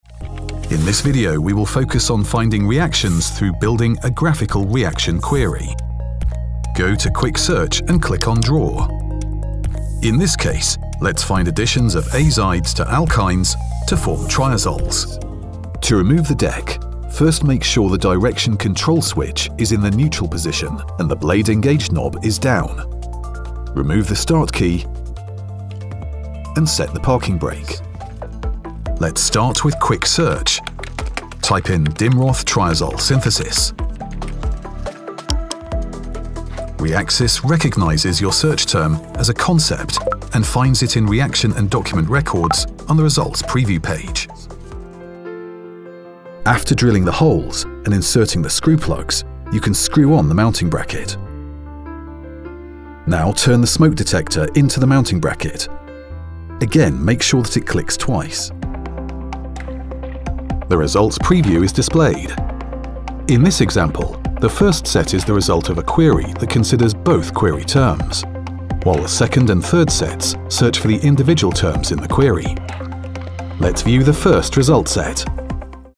Engels (Brits)
Commercieel, Diep, Natuurlijk, Opvallend, Vertrouwd
Explainer